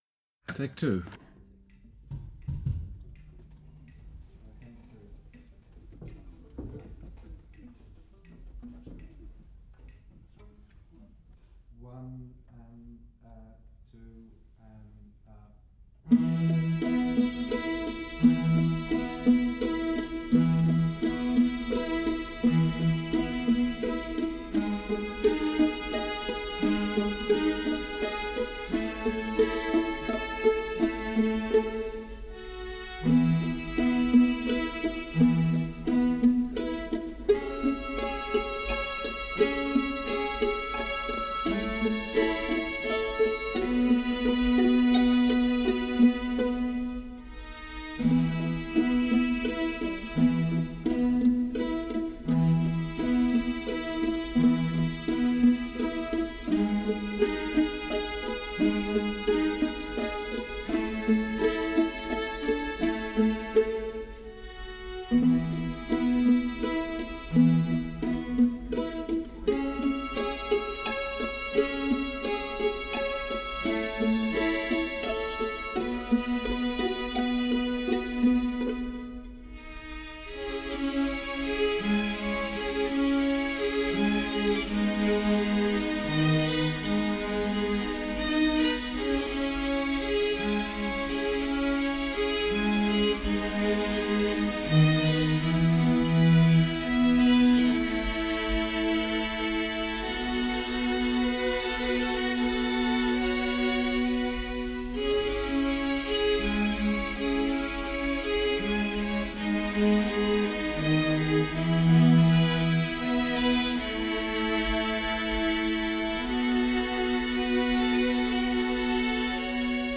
Classical
موسیقی کلاسیک